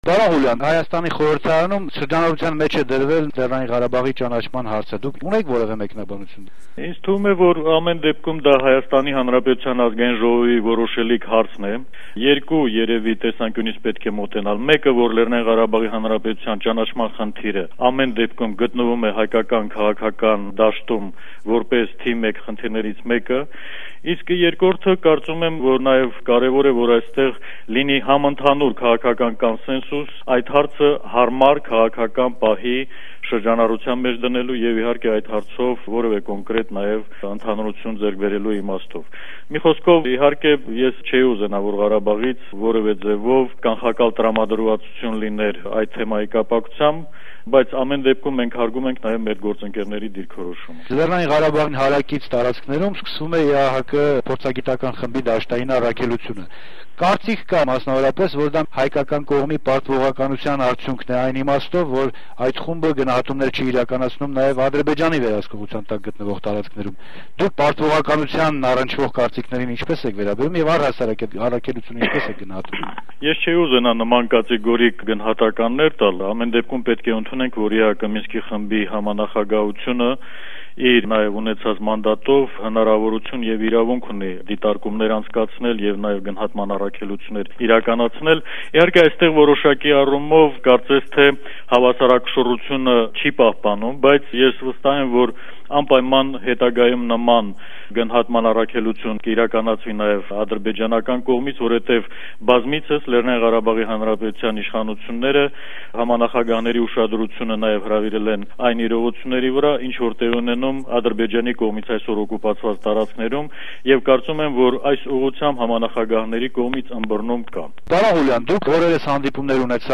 Լեռնային Ղարաբաղի Ազգային ժողովի նախագահ Աշոտ Ղուլյանի հետ հարցազրույցը